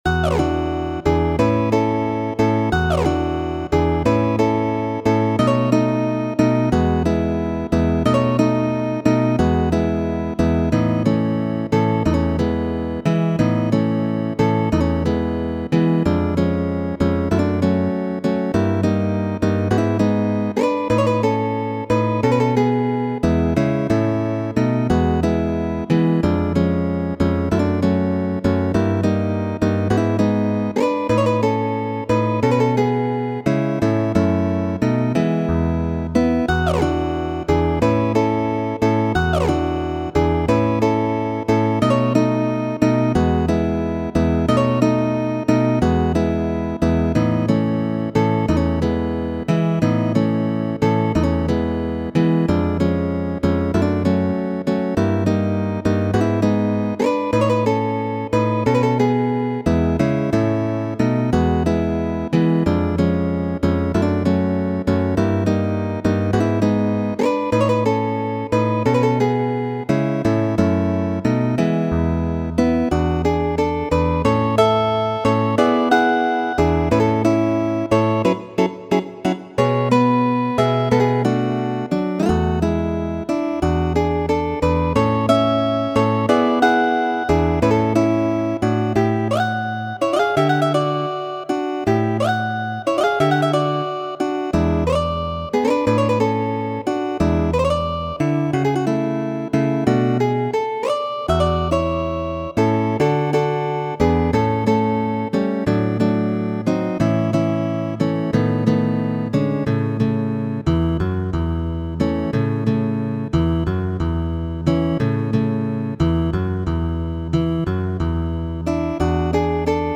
Eble plaĉos al vi aŭskulti la delikatan Danco Odalisca (danco de la sklavino).